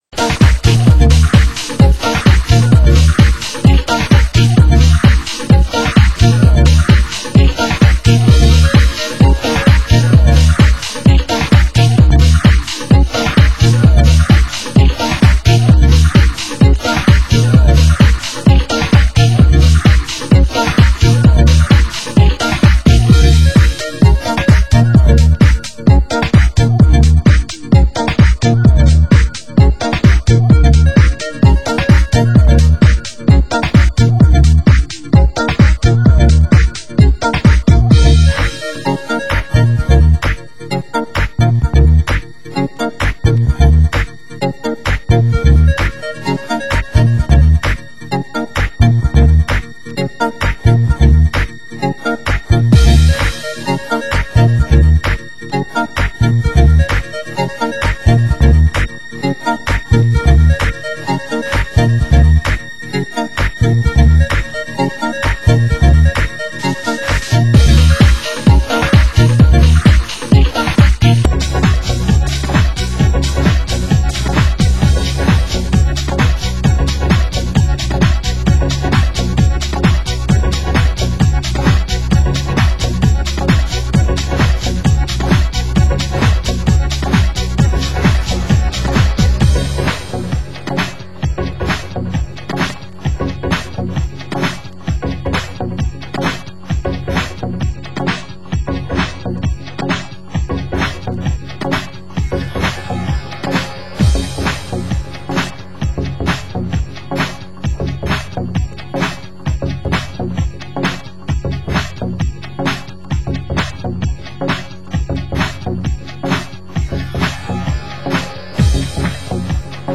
Genre: Euro House